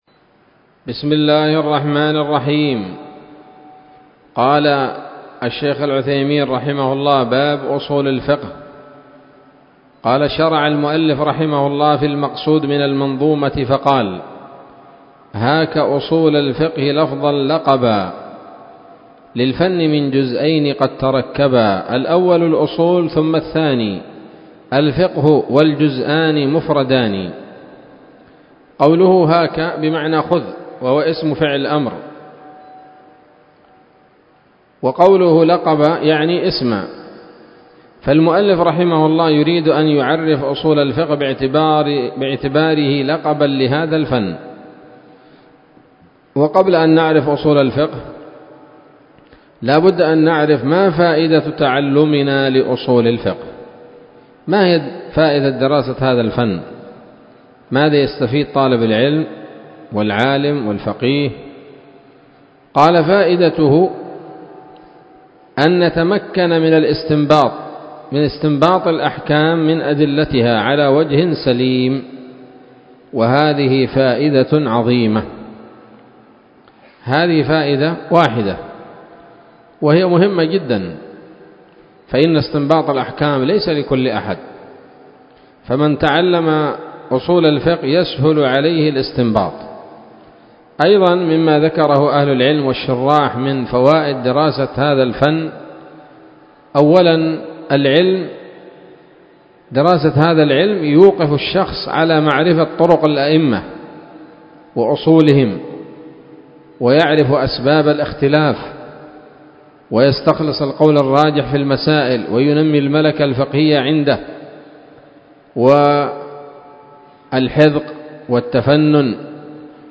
الدرس الخامس من شرح نظم الورقات للعلامة العثيمين رحمه الله تعالى